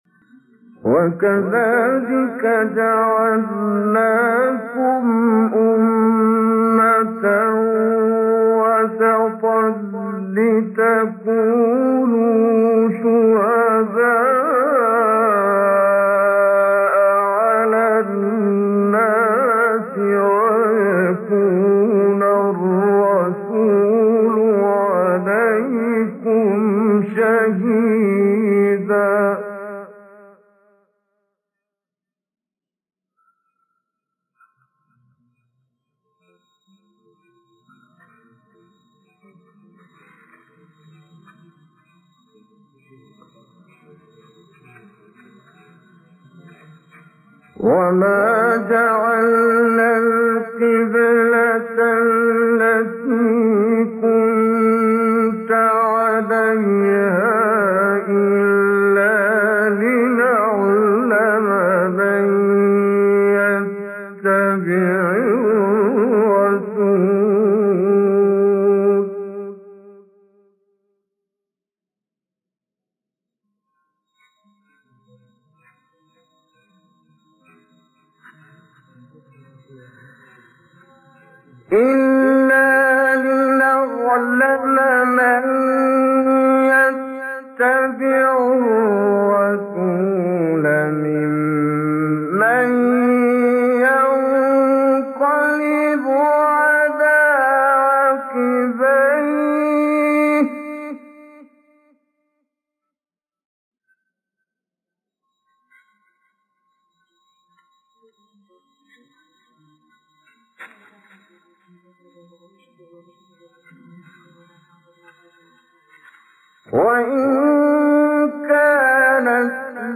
آیه 143 سوره بقره استاد کامل یوسف | نغمات قرآن | دانلود تلاوت قرآن